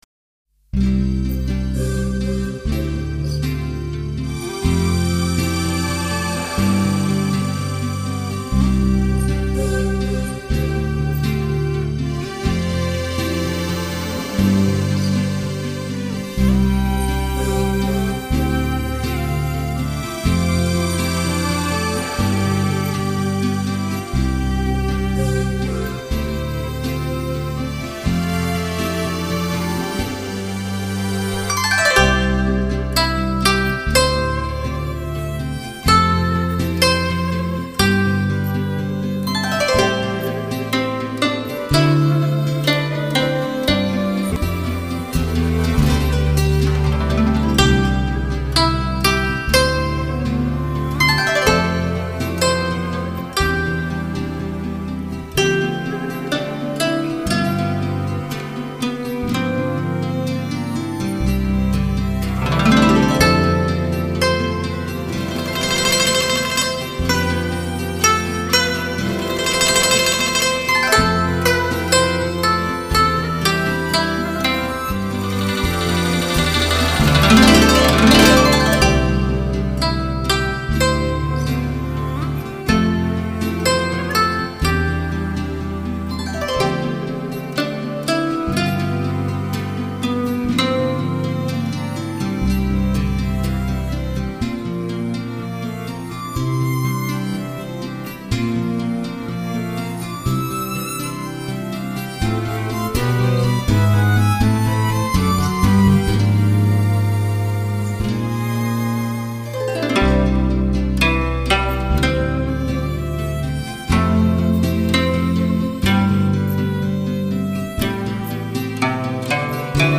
中西精华乐器的融合
成就当今最完美的轻音乐 来净化你烦躁的心情
东方神韵 神秘奥妙 典雅古朴
西方神韵 自由浪漫 栩栩如生